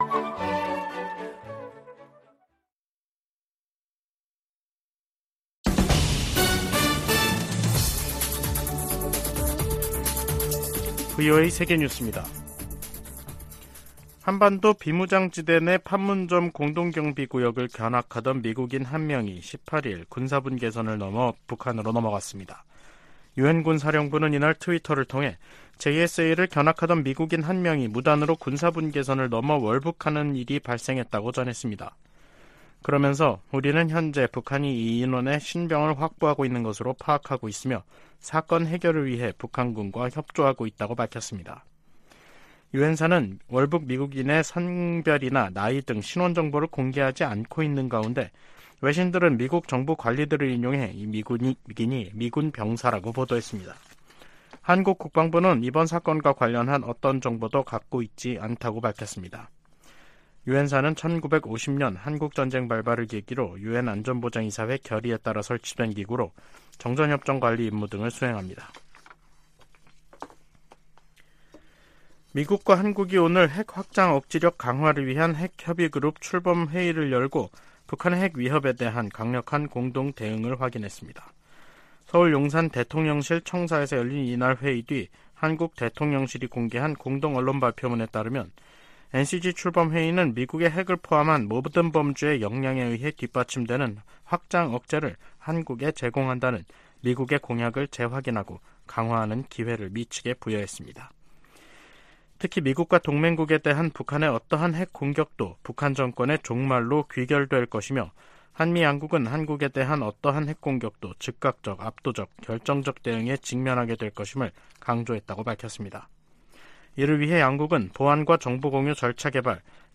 VOA 한국어 간판 뉴스 프로그램 '뉴스 투데이', 2023년 7월 18일 3부 방송입니다. 미국과 한국은 18일 서울에서 핵협의그룹(NCG) 첫 회의를 갖고 북한이 핵 공격을 할 경우 북한 정권은 종말을 맞을 것이라며, 확장억제 강화의지를 재확인했습니다. 미 국무부는 북한의 도발에 대한 유엔 안보리의 단합된 대응을 촉구했습니다. 아세안지역안보포럼(ARF)이 의장성명을 내고, 급증하는 북한의 탄도미사일 발사가 역내 평화를 위협한다고 비판했습니다.